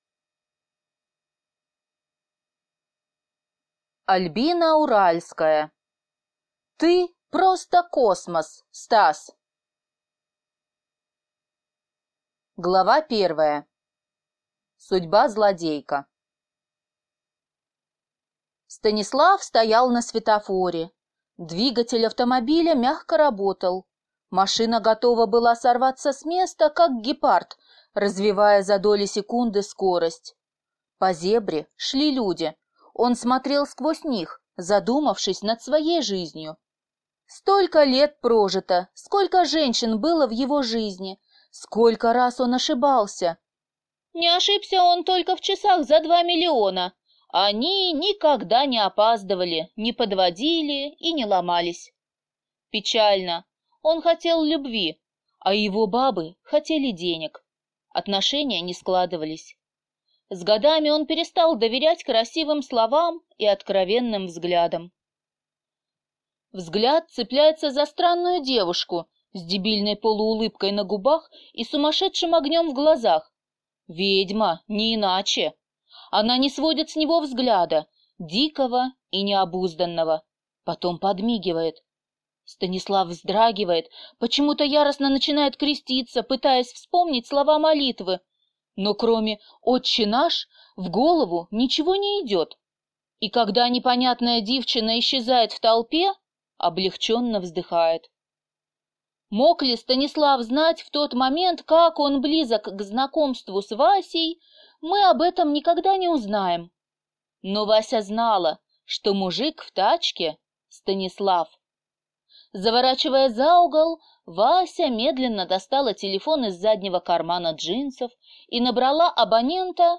Аудиокнига Ты просто космос, Стас!